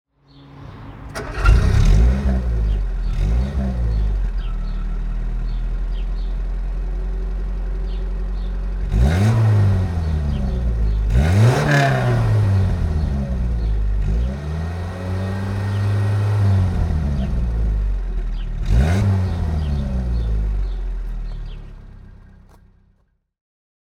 Renault 12 TL (1974) - Starten und Leerlauf